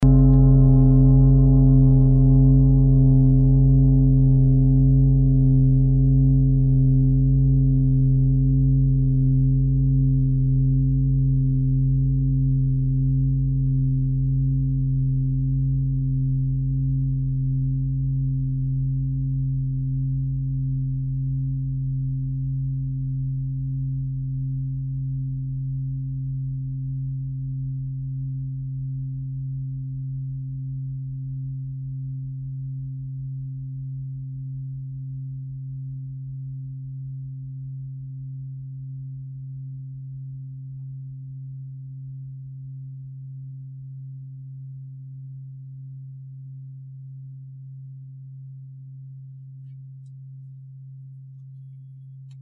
Fuss-Klangschale Nr.1, Planetentonschale: Wasserfrequenz
Klangschalen-Durchmesser: 56,0cm
(Ermittelt mit dem Gummischlegel)
Die Klangschale hat bei 44.67 Hz einen Teilton mit einer
Signalintensität von 100 (stärkstes Signal = 100) :
Die Klangschale hat bei 133.3 Hz einen Teilton mit einer
fuss-klangschale-1.mp3